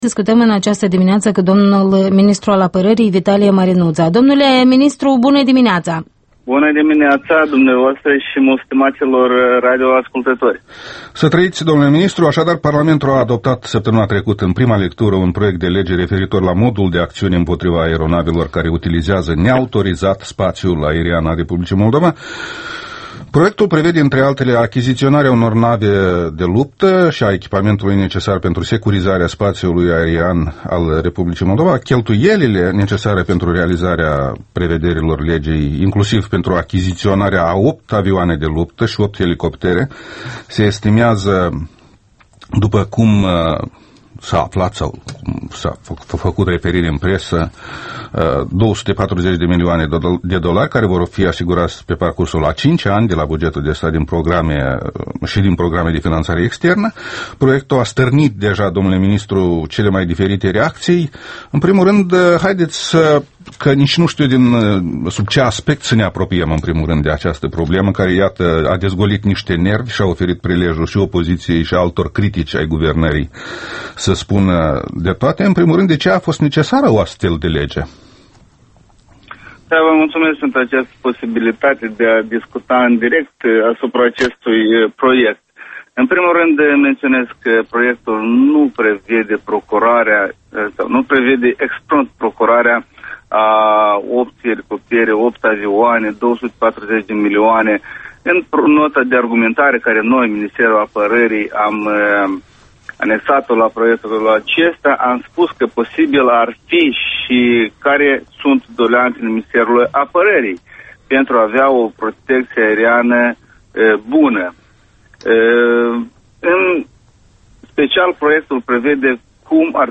Interviul dimineții la Europa Liberă: cu ministrul apărării Vitalie Marinuță